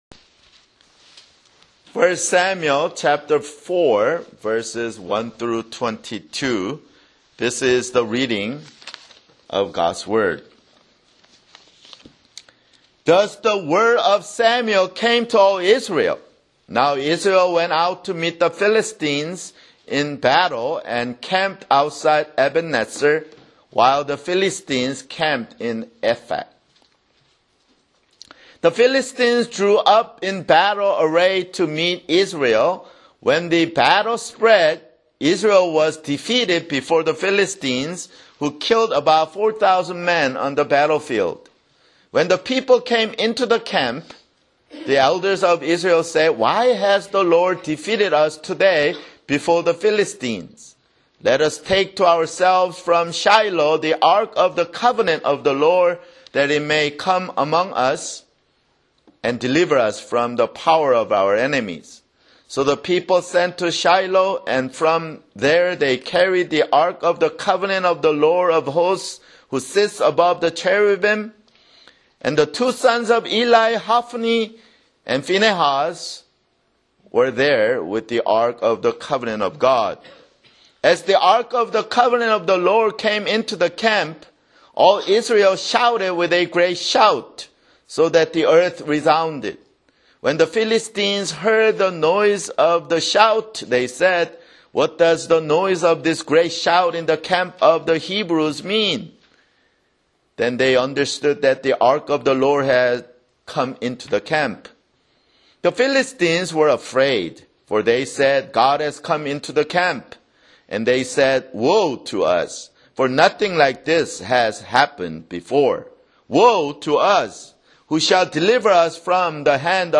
[Sermon] 1 Samuel (18)